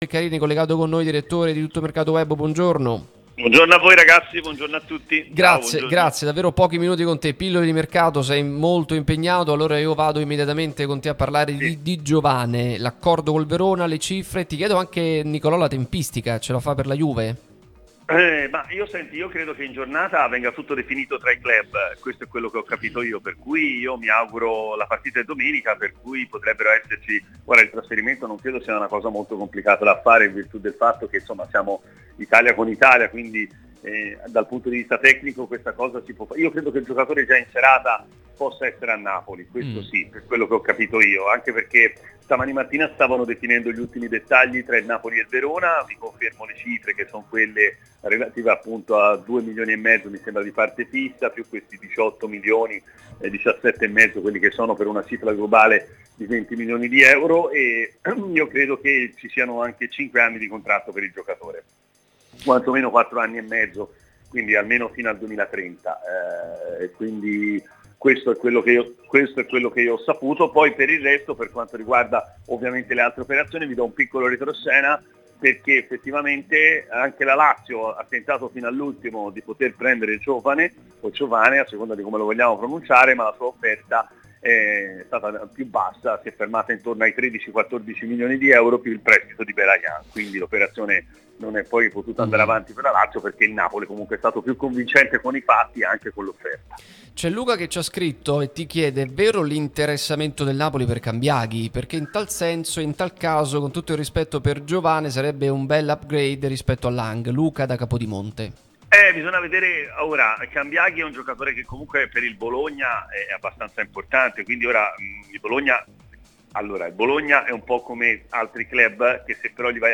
prima radio tematica sul Napoli